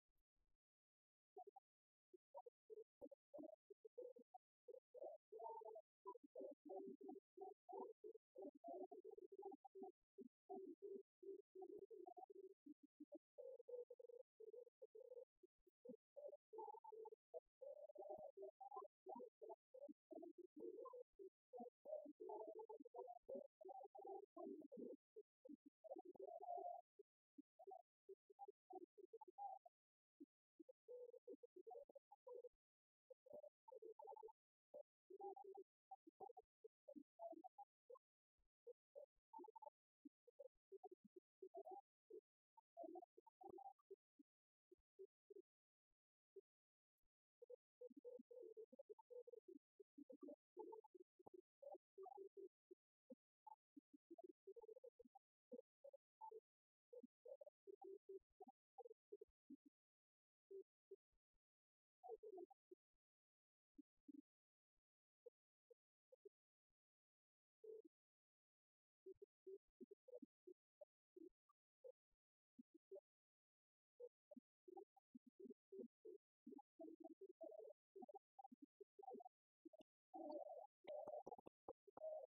Mémoires et Patrimoines vivants - RaddO est une base de données d'archives iconographiques et sonores.
Scottish à sept pas
Chants brefs - A danser
danse : scottich sept pas
Chansons du Club des retraités
Pièce musicale inédite